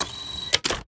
coilgunReload.ogg